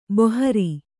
♪ bohari